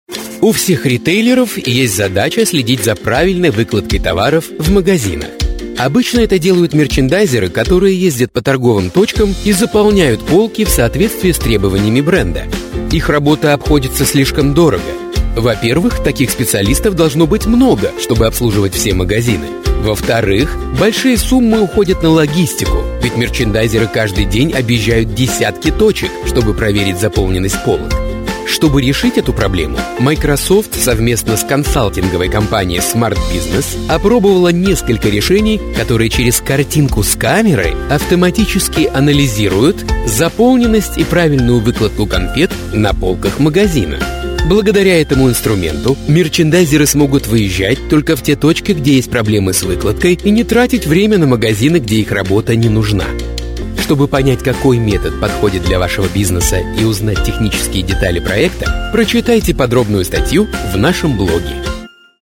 Kein Dialekt
Sprechprobe: eLearning (Muttersprache):
Studio Equipment: 4×6 Double-wall booth by “Whisper Room” Microphones: Rode NT2000 Condencer Mic. AT5040 Condencer Mic. Sennhieser 416 Condencer Mic. Sennhieser 441U Dynamic Mic. Shure SM7B Dynamic Mic. Other stuff: Presonus “Studio Live” mixer board (Firewire) MacPro Computer Source Connect, Phone/Skype patch. FiOS Internet Connection
Explainer.mp3